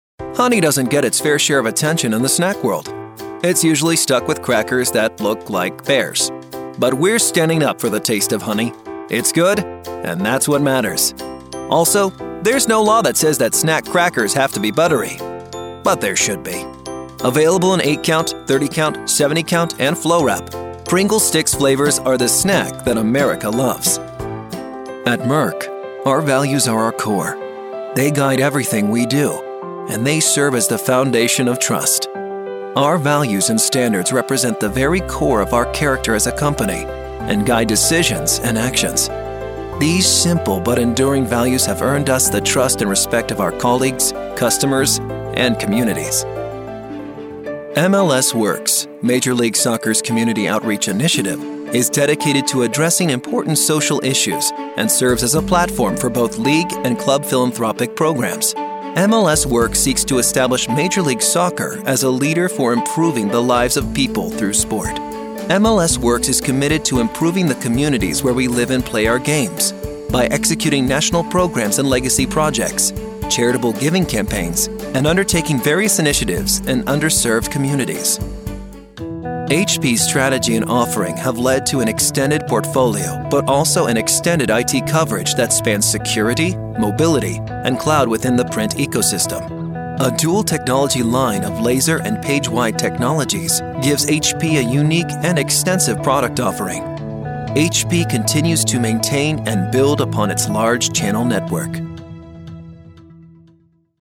Corporate Videos
Everyman
Guy Next Door
English (American)
SincereEpicFreshVersatileExcitingSmoothNaturalConversational